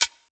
click.ogg